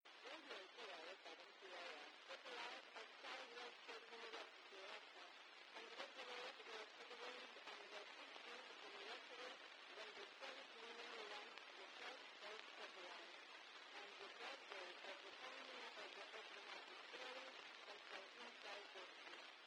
Heard voice greetings from Tanusha-2
on 145,800 MHZ in FM over JN77sn.